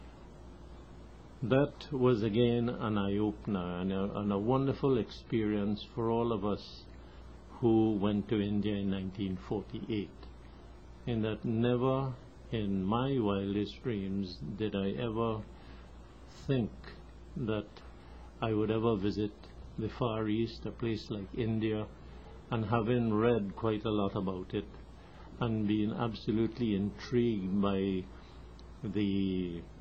4 audio cassettes
The Oral and Pictorial Records Programme (OPReP)